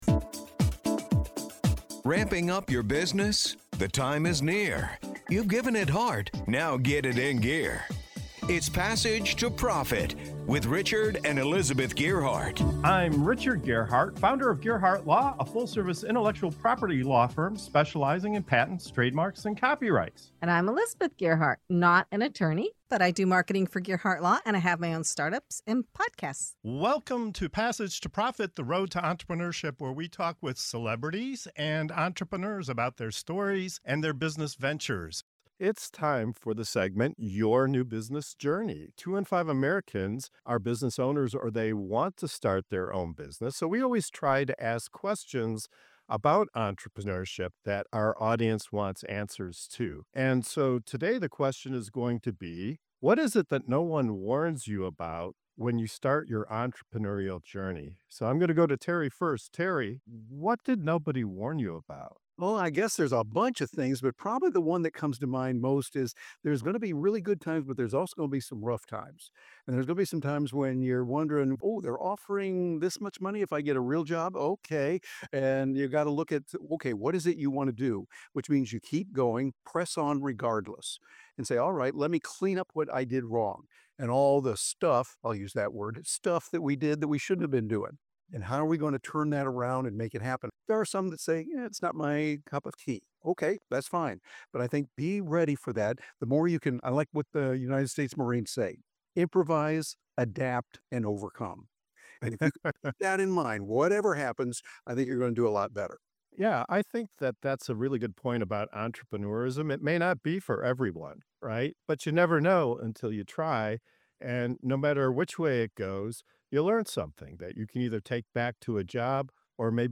Starting a business is an adventure filled with highs, lows, and unexpected challenges. In this episode, our guests reveal the hard truths no one warns you about when diving into entrepreneurship—whether it’s navigating financial uncertainty, learning to scale, or hiring the right people.